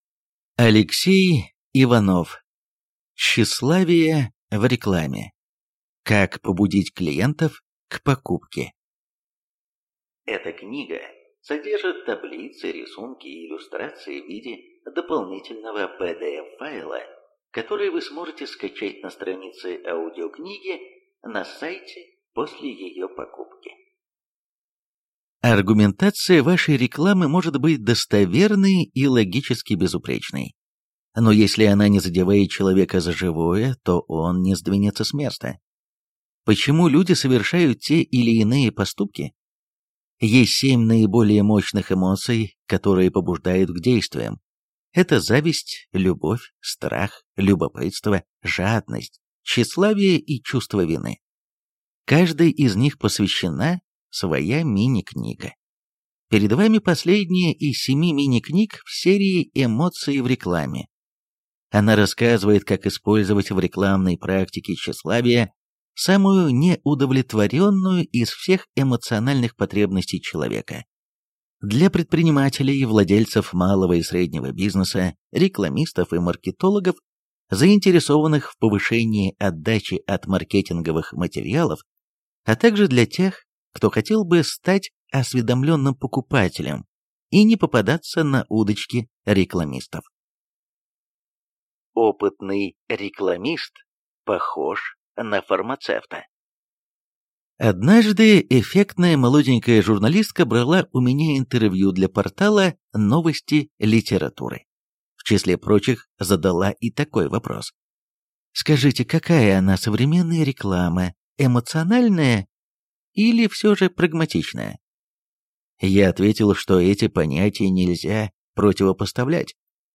Аудиокнига Тщеславие в рекламе. Как побудить клиентов к покупке | Библиотека аудиокниг